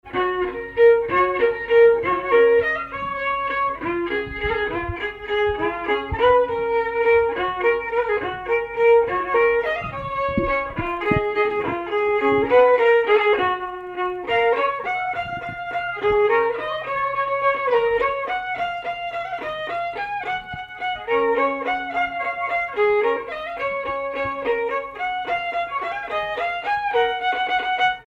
Valse
danse : valse
circonstance : bal, dancerie
Pièce musicale inédite